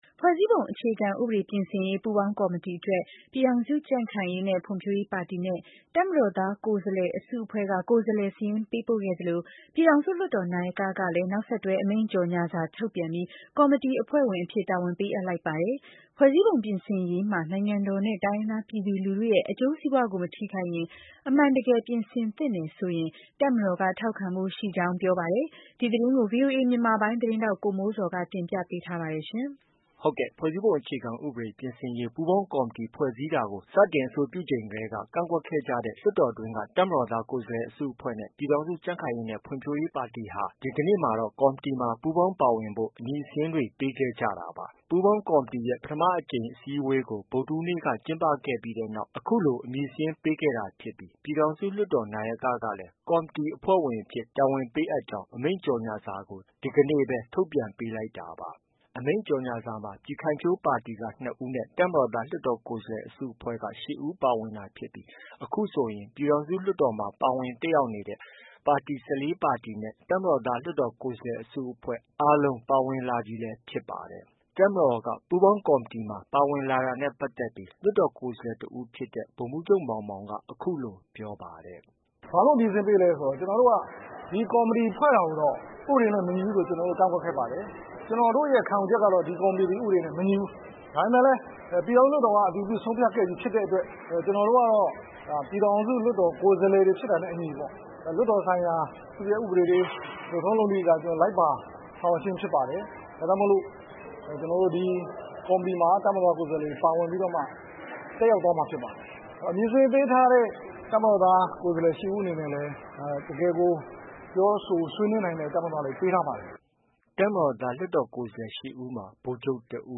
တပ်မတော်က ပူးပေါင်းကော်မတီပါဝင်လာတာနဲ့ပတ်သက်ပြီး လွှတ်တော်ကိုယ်စားတစ်ဦးဖြစ်တဲ့ ဗိုလ်မှူးချုပ်မောင်မောင်က အခုလို ပြောပါတယ်။